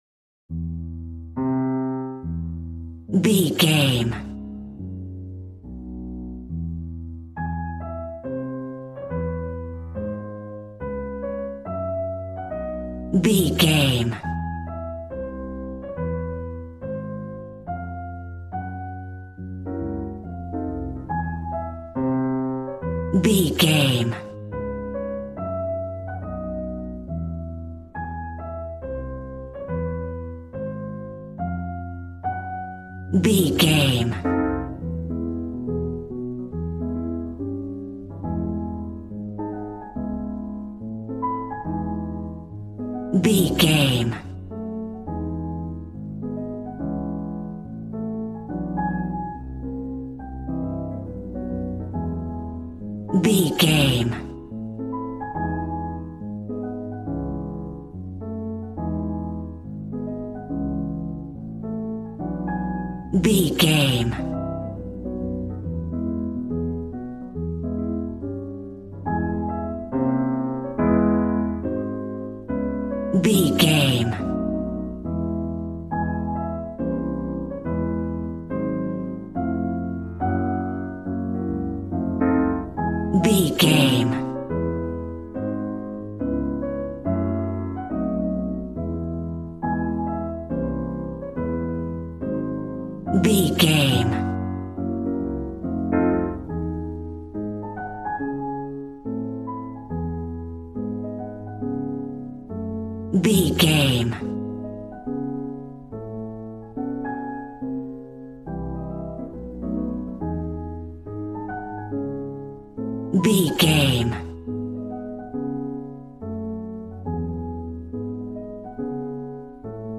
Smooth jazz piano mixed with jazz bass and cool jazz drums.,
Ionian/Major